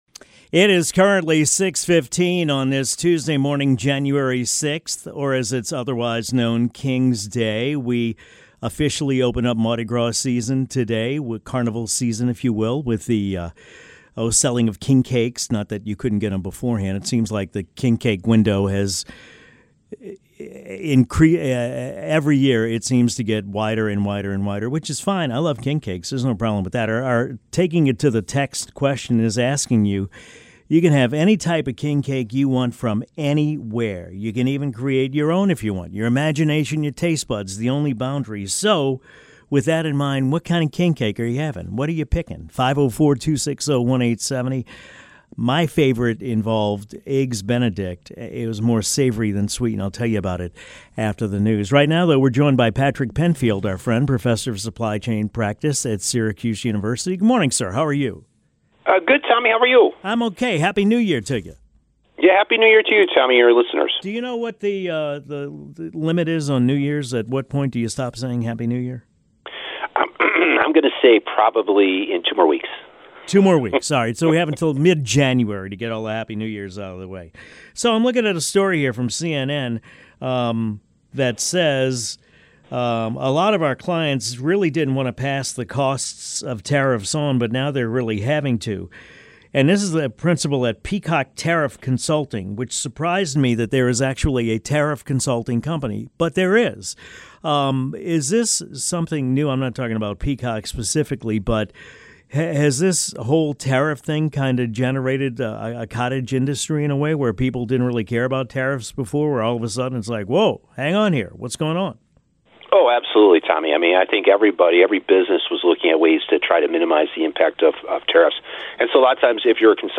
1 Sunderland vs Sheff Utd: Black Cats are back in the Premier League after late Wembley drama! 47:35 Play Pause 7m ago 47:35 Play Pause Play later Play later Lists Like Liked 47:35 David Prutton, Jobi McAnuff, Curtis Davies and Russell Martin are pitch side at Wembley to look back on Sunderland's 2-1 win in the Championship play-off final against Sheffield United. Hear the full-time whistle noise from the Sunderland fans and listen to some of their victorious players who sealed the club's return to the Premier League after ei…